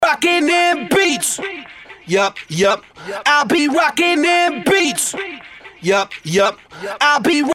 S – ROCKIN DEM BEATS – 128BPM
S-ROCKIN-DEM-BEATS-128BPM.mp3